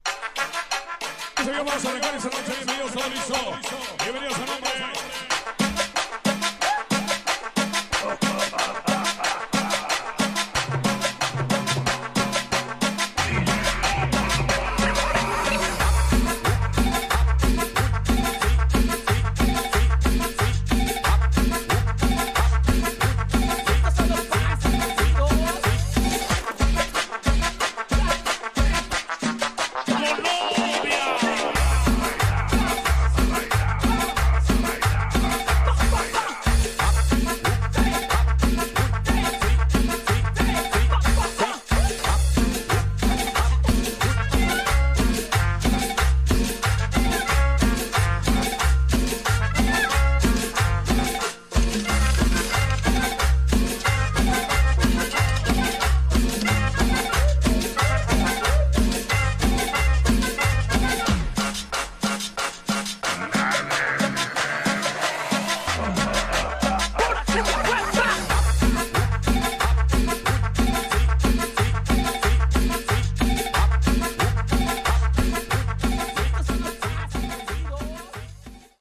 Tags: Cumbia , Sonidero , Mexico
Pura cumbia wepa say no more folks.